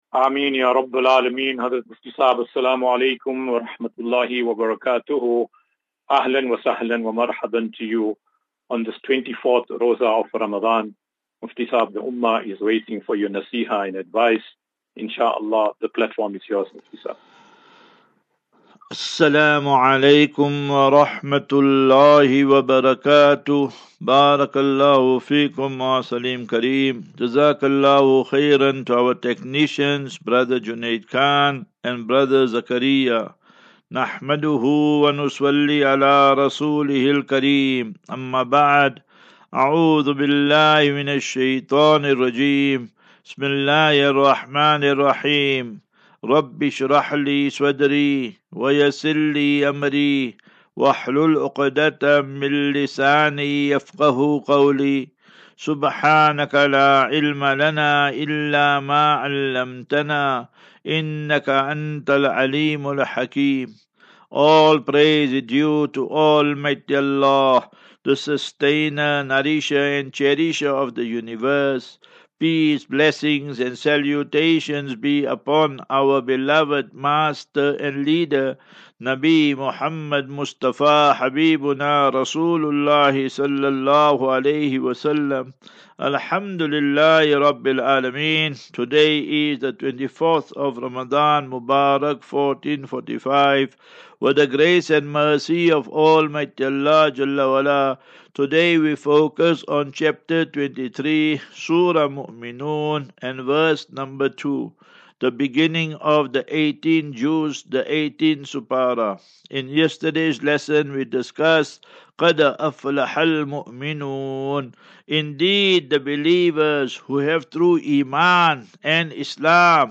Daily Naseeha.
As Safinatu Ilal Jannah Naseeha and Q and A 4 Apr 04 April 2024.